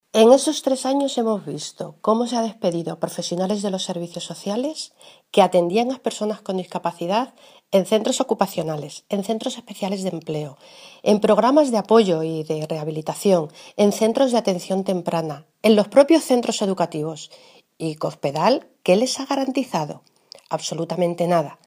La diputada nacional del PSOE, Guadalupe Martín, ha señalado hoy que el proyecto de ley de garantía de los derechos de las personas con discapacidad, que ayer aprobó el Consejo de Gobierno de Castilla-La Mancha, “llega tarde, es electoralista y supone una falta de respeto a los discapacitados de nuestra región, que han venido sufriendo los recortes de Cospedal durante todos estos años”.
Cortes de audio de la rueda de prensa